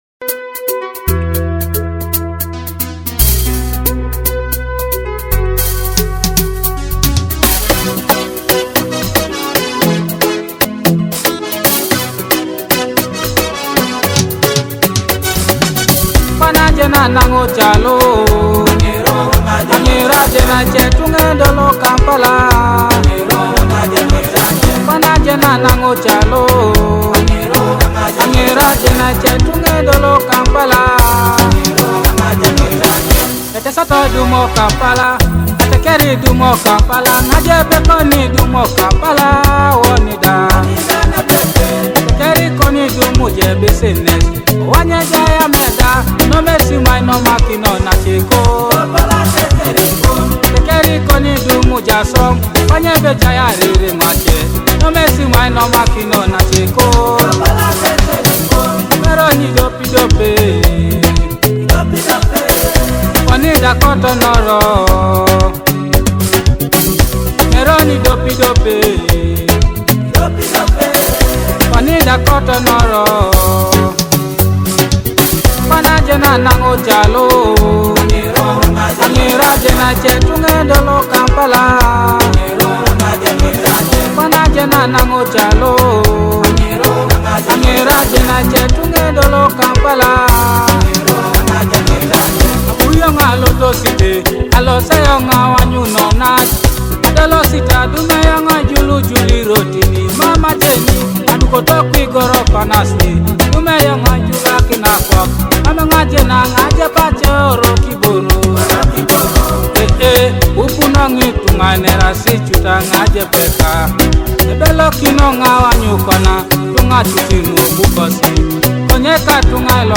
uplifting sound